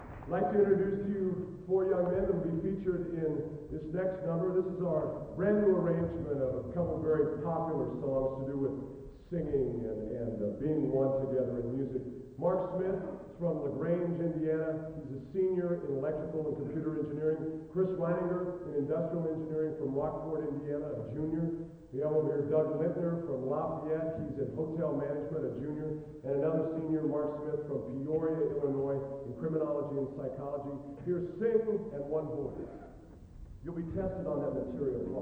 introducing next song soloists
Collection: South Bend 1990